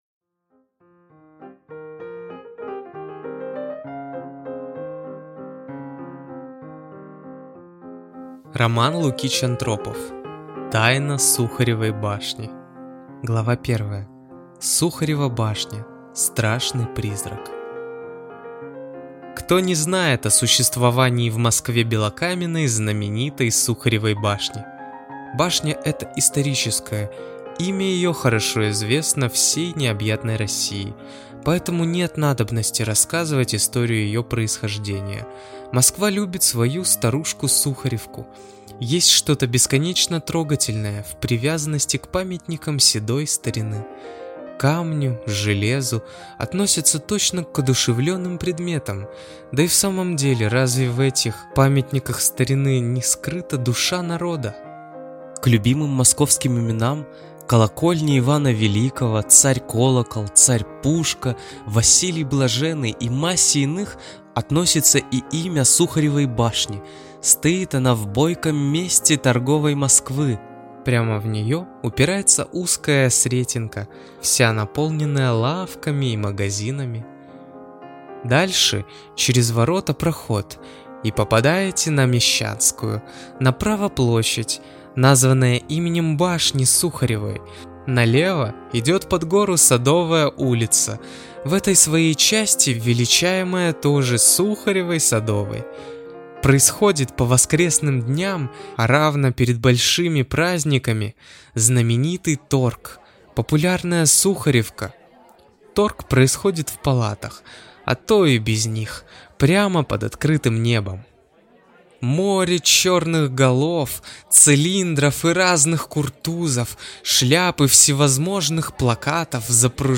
Аудиокнига Тайна Сухаревой башни | Библиотека аудиокниг